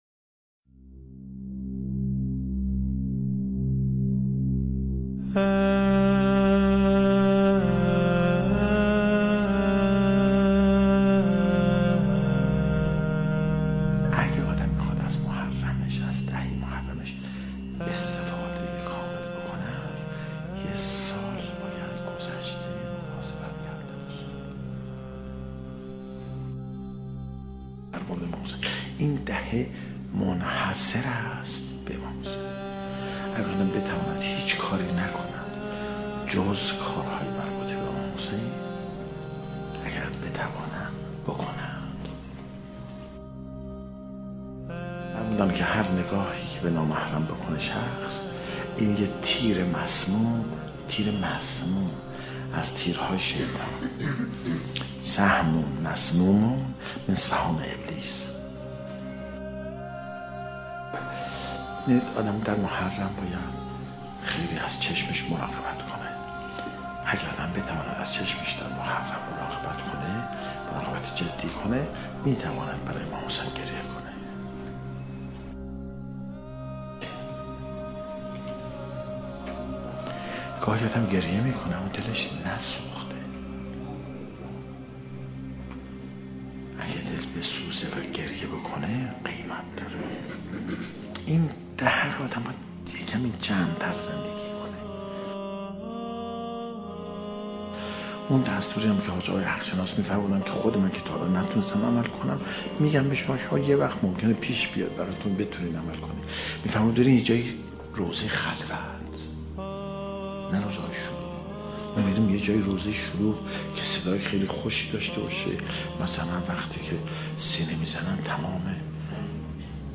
سخنرانی | عزاداری واقعی برای امام حسین(ع) در دهه محرم